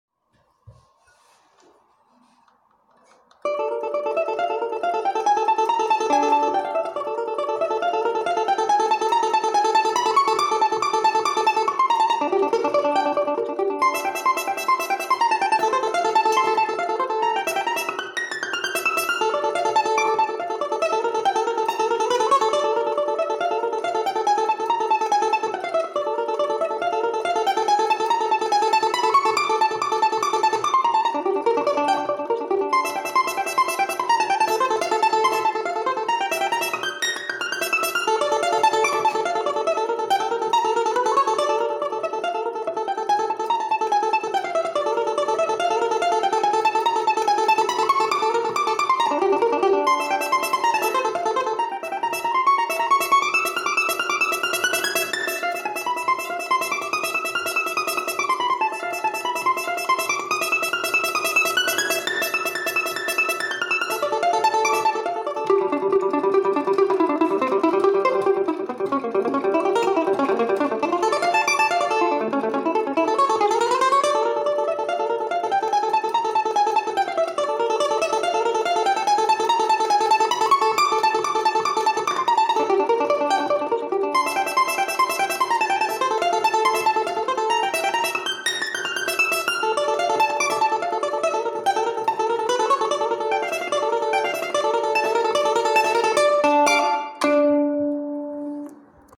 Домра - Новый пример